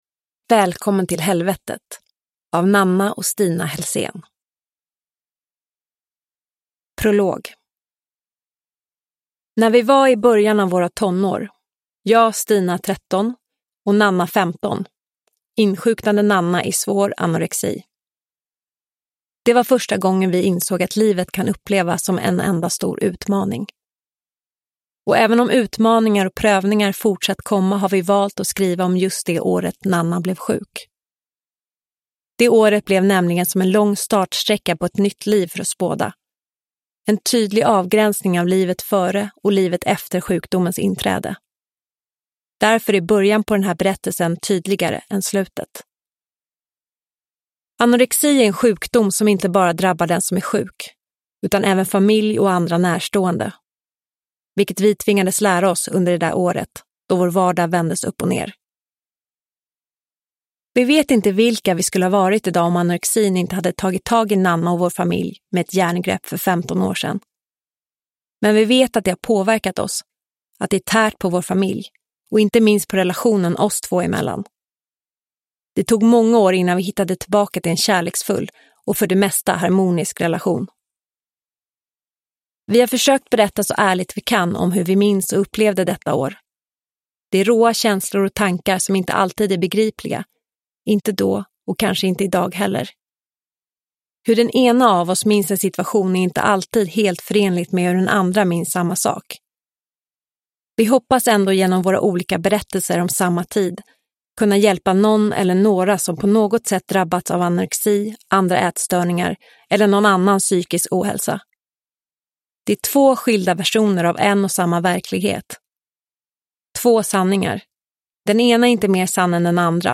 Välkommen till helvetet – Ljudbok